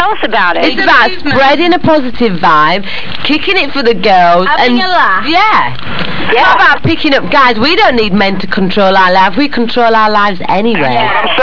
(all files here are in *.wav format. Recorded at 11025 Hz in 8 bit mono)